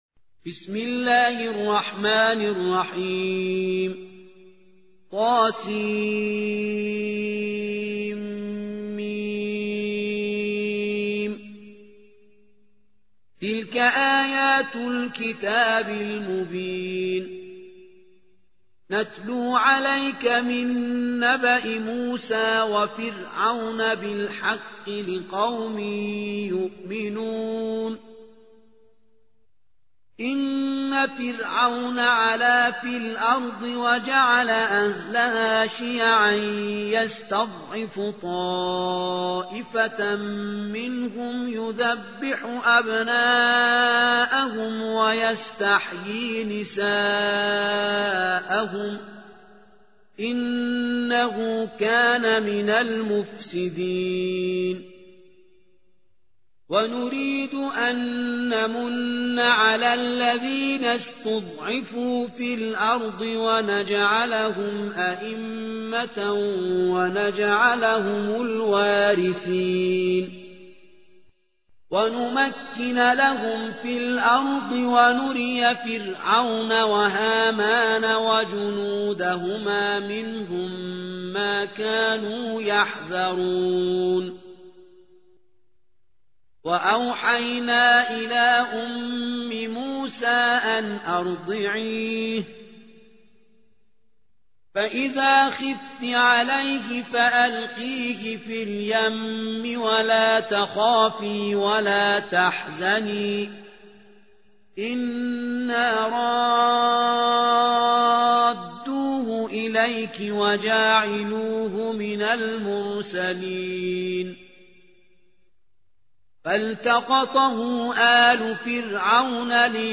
28. سورة القصص / القارئ